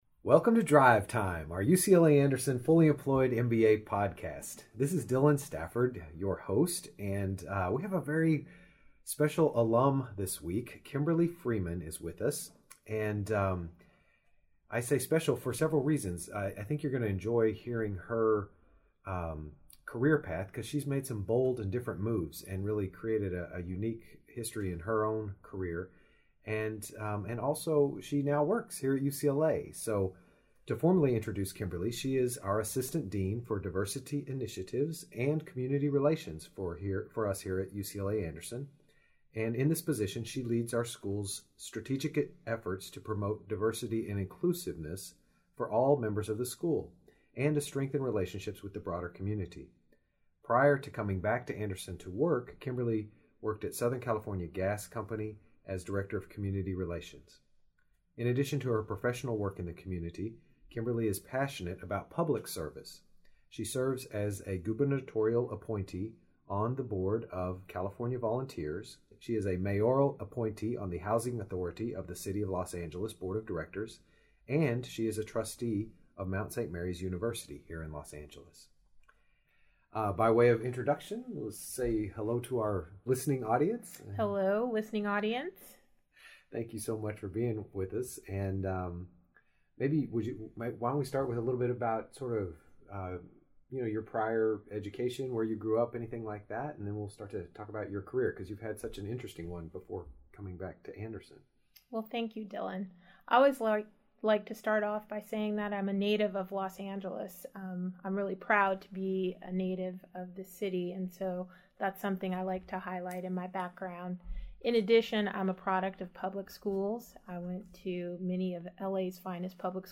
We hope you enjoy this interview with a very successful UCLA Anderson alumna